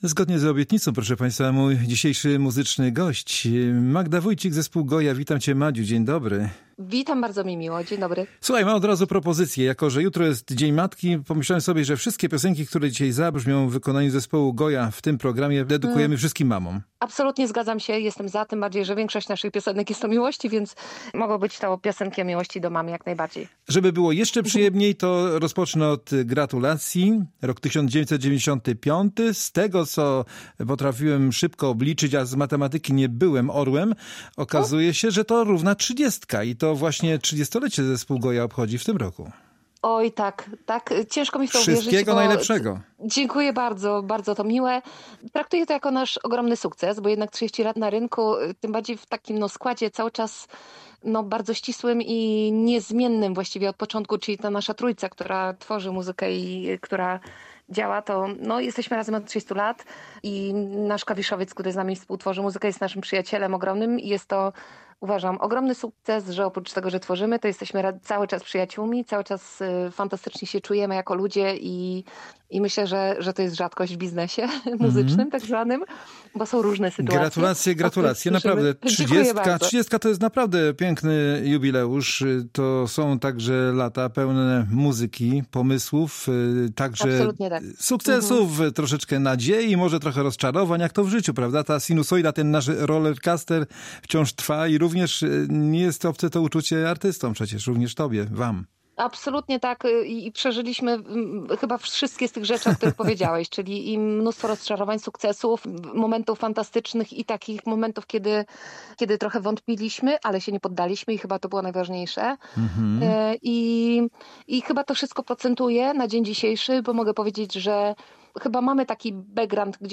Na naszej antenie gościliśmy wokalistkę, autorkę tekstów, kompozytorkę i gitarzystkę grupy GOYA Magdę Wójcik.